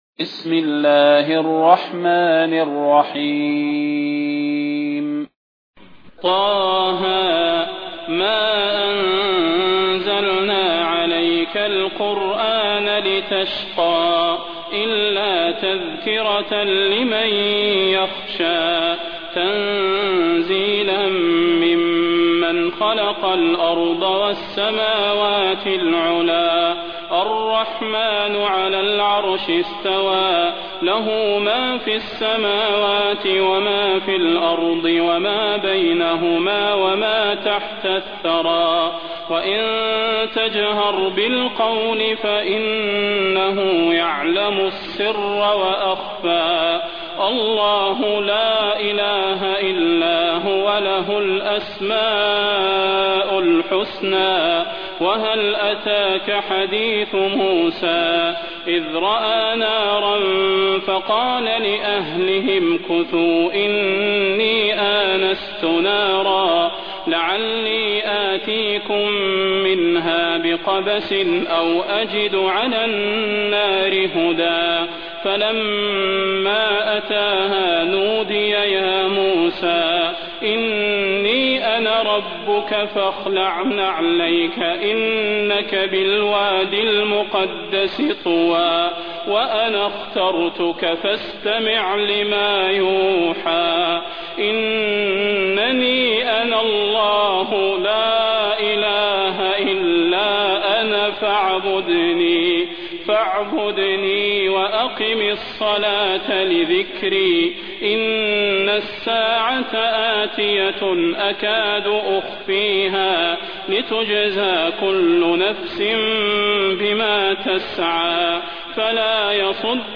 المكان: المسجد النبوي الشيخ: فضيلة الشيخ د. صلاح بن محمد البدير فضيلة الشيخ د. صلاح بن محمد البدير طه